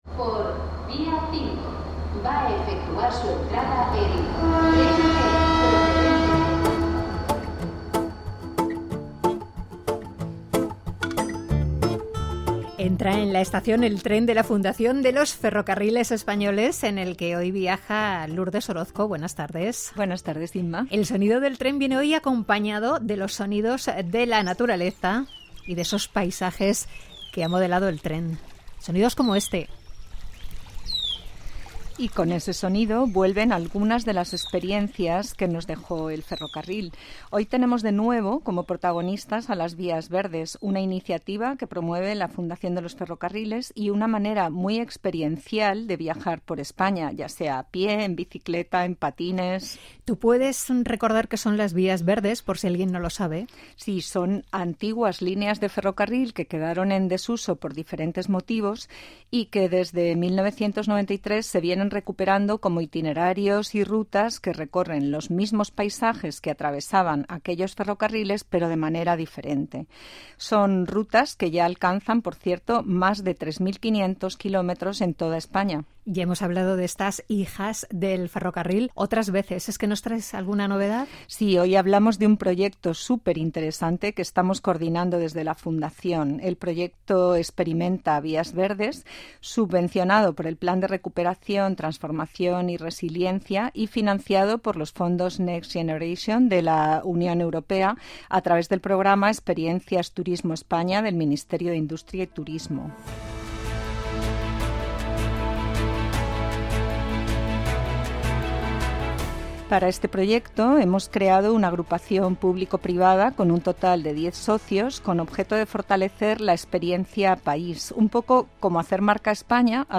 En esta ocasión participaron varios socios de la agrupación público-privada que se encarga de esta iniciativa para explicar los avances más importantes. El trabajo que realizan se enfoca en la creación de redes de actores en las vías verdes, la integración del patrimonio ferroviario y la mejora de la calidad de la 'experiencia vías verdes'.